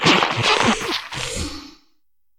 Cri de Filentrappe dans Pokémon HOME.